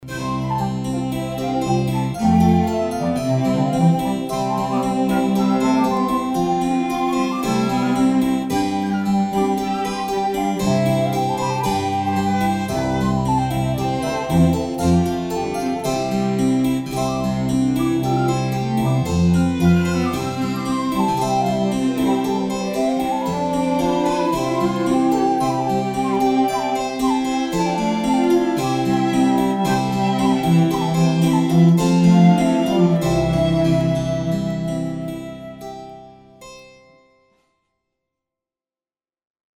Harp
Instrumental